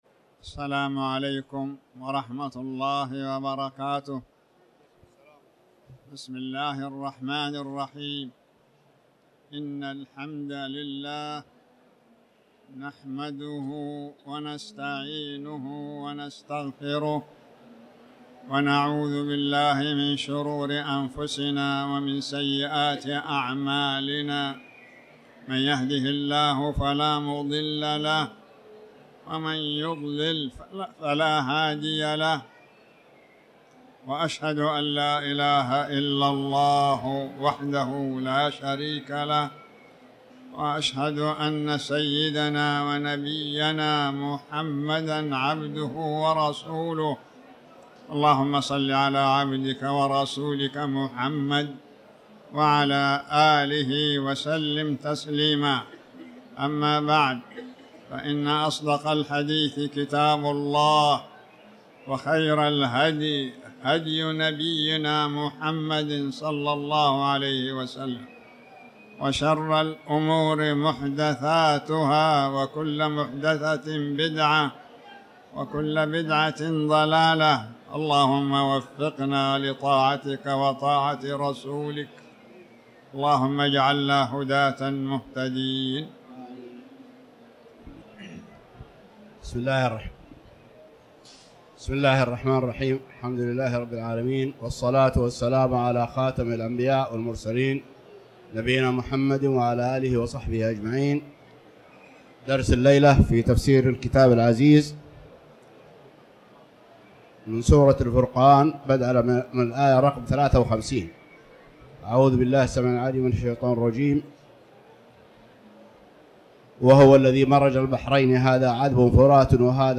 تاريخ النشر ١٠ شعبان ١٤٤٠ هـ المكان: المسجد الحرام الشيخ